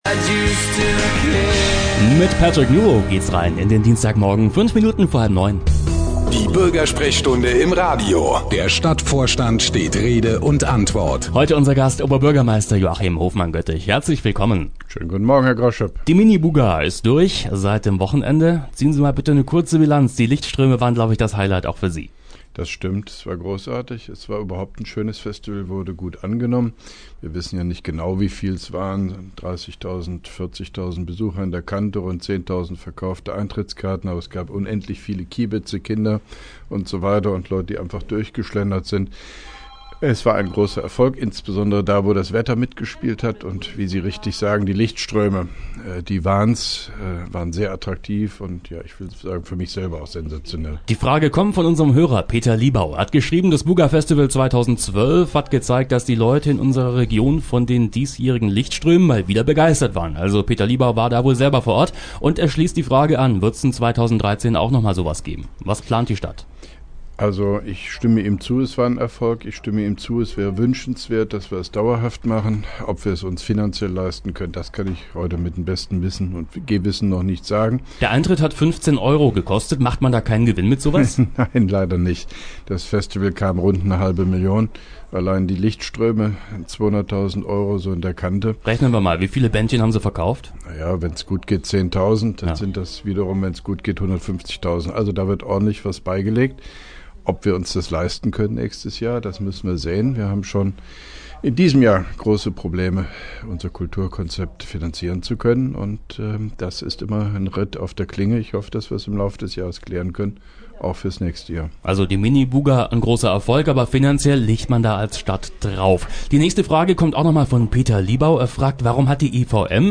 (1) Koblenzer Radio-Bürgersprechstunde mit OB Hofmann-Göttig 22.05.2012
Antenne Koblenz 98,0 am 22.05.2012, ca. 8.25 Uhr, (Dauer 03:30 Minuten)